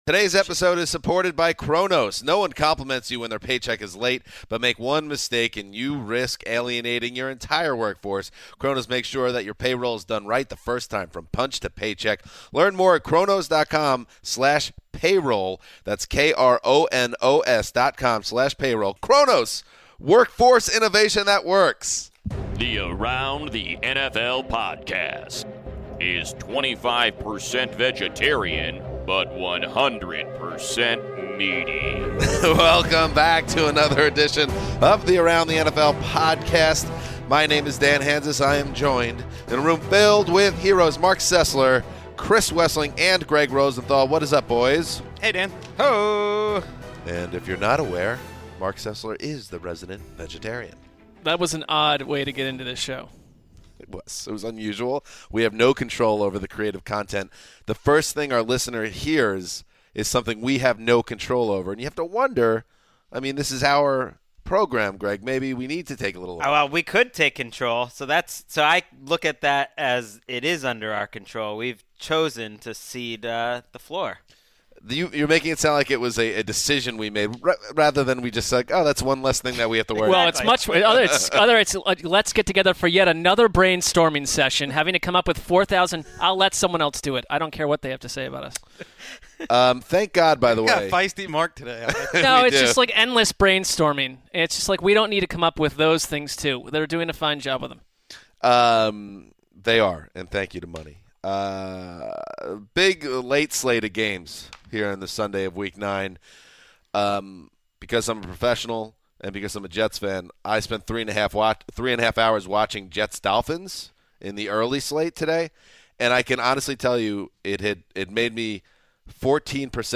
The heroes then have a rollicking conversation about where Carolina fits in the NFC playoff picture (14:30), the Steelers prevailing in their AFC North battle against the Ravens (19:00) & the Chargers final stand in Seattle (34:30).